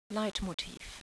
Read each word to yourself in the way that a German would pronounce it and then click on the sound icon next to each word to listen to it being spoken a German native speaker.